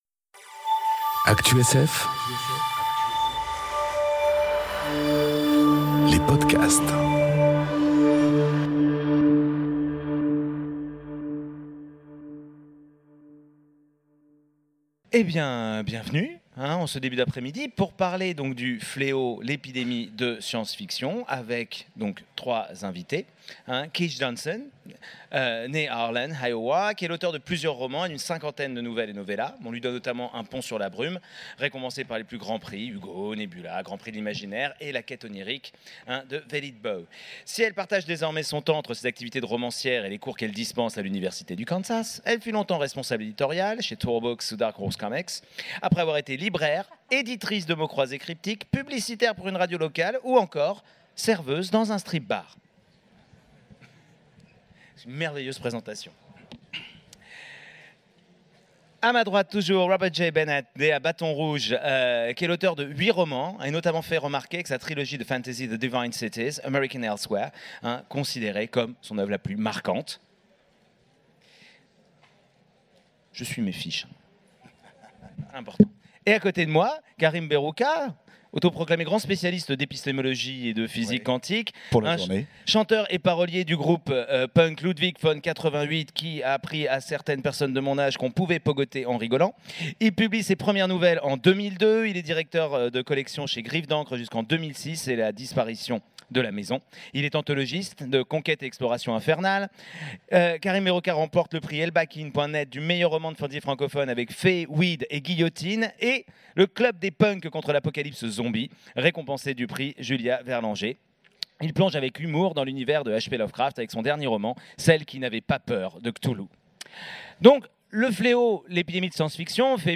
Conférence Le fléau : l'épidémie de science-fiction enregistrée aux Utopiales 2018